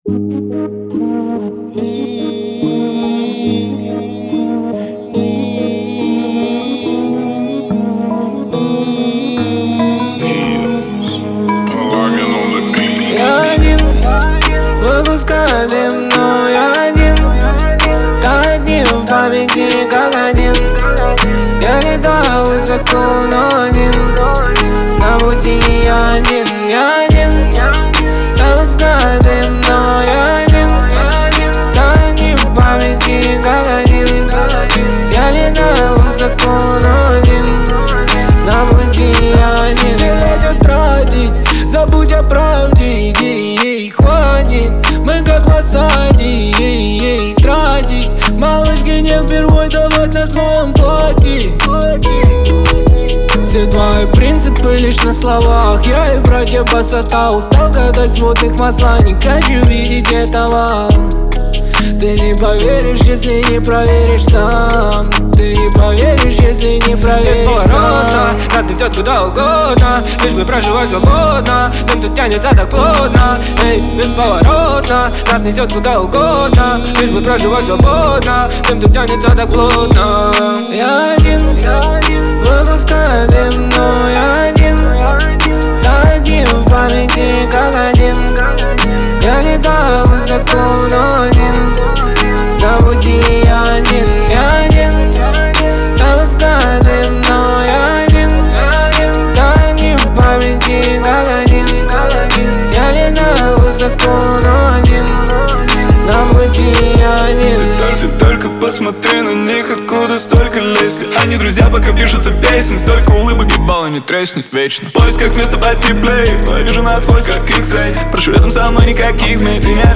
выделяется атмосферными битами и запоминающейся мелодией